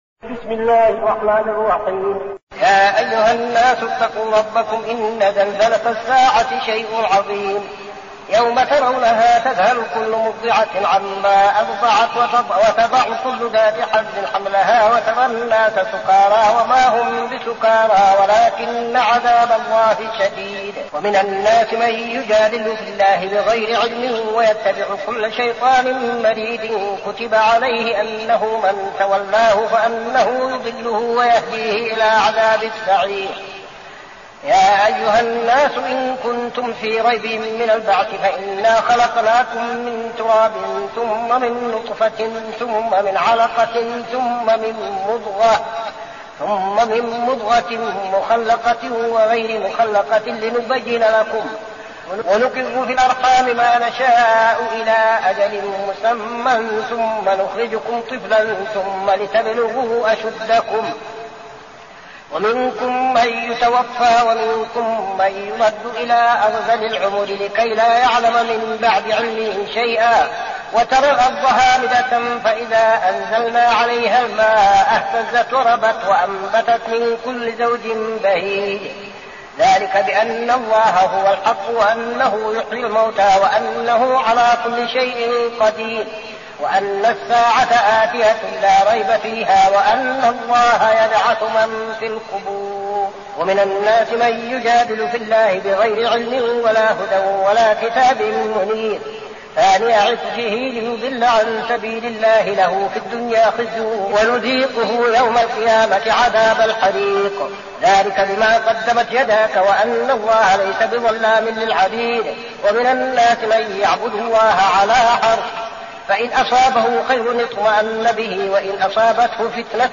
المكان: المسجد النبوي الشيخ: فضيلة الشيخ عبدالعزيز بن صالح فضيلة الشيخ عبدالعزيز بن صالح الحج